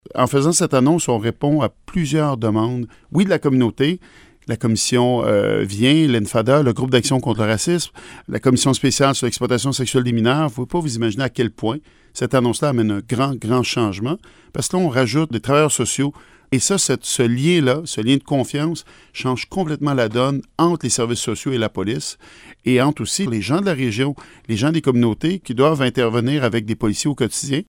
Voici les propos du ministre du Secrétariat aux affaires autochtones, Ian Lafrenière :